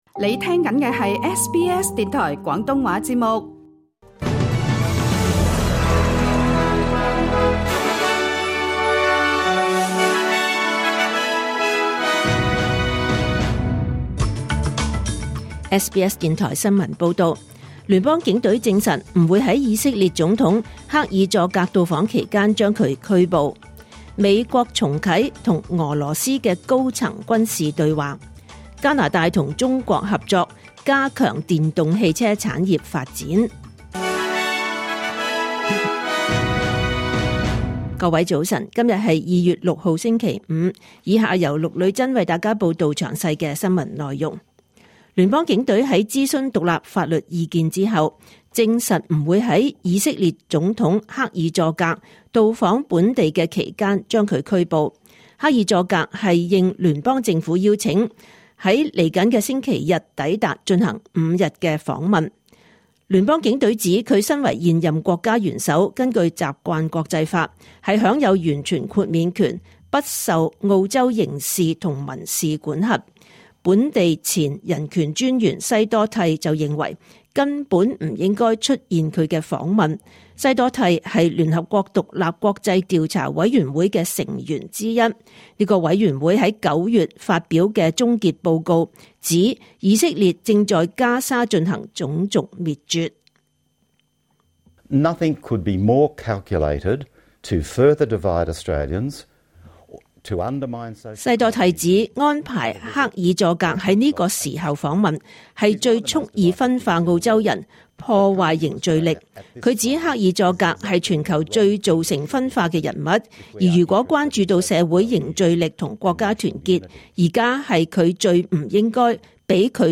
2026年 2 月 6 日SBS廣東話節目九點半新聞報道。